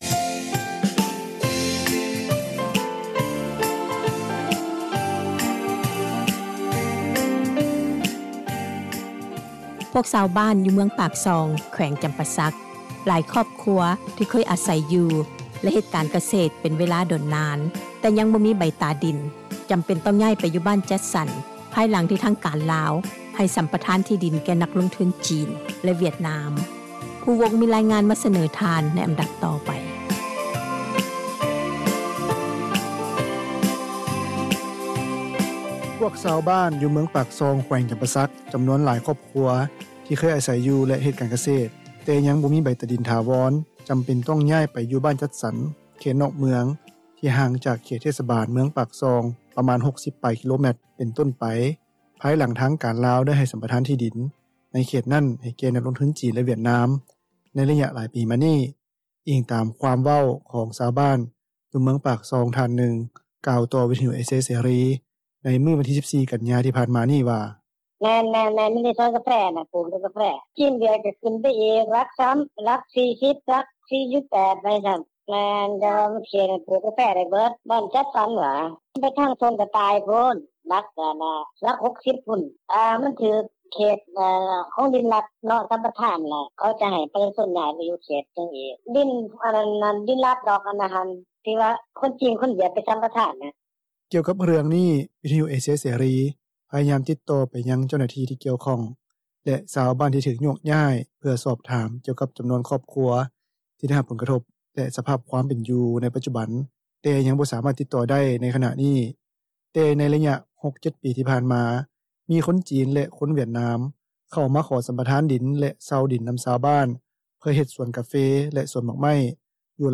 ພວກຊາວບ້ານ ຢູ່ເມືອງປາກຊ່ອງ ແຂວງຈຳປາສັກ ຈຳນວນຫຼາຍຄອບຄົວ ທີ່ເຄີຍອາສັຍຢູ່ ແລະ ເຮັດການກະເສຕ ເປັນເວລາດົນນານ, ແຕ່ຍັງບໍ່ມີໃບຕາດິນຖາວອນ ຈຳເປັນຕ້ອງຍ້າຍໄປຢູ່ບ້ານຈັດສັນ ເຂດນອກເມືອງ ທີ່ຫ່າງຈາກເຂດເທສບານ ເມືອງປາກຊ່ອງ ປະມານ 60 ປາຍກິໂລແມັຕ ເປັນຕົ້ນໄປ ພາຍຫຼັງທາງການລາວ ໄດ້ໃຫ້ສັມປະທານທີ່ດິນ ໃນເຂດນັ້ນ ໃຫ້ແກ່ນັກລົງທຶນຈີນ ແລະ ວຽດນາມ ໃນໄລຍະຫຼາຍປີມານີ້, ອີງຕາມຄວາມເວົ້າ ຂອງຊາວບ້ານ ຢູ່ເມືອງປາກຊ່ອງ ທ່ານນຶ່ງ ກ່າວຕໍ່ວິທຸຍເອເຊັຽເສຣີ ໃນມື້ວັນທີ 14 ກັນຍາ ທີ່ຜ່ານມານີ້ວ່າ: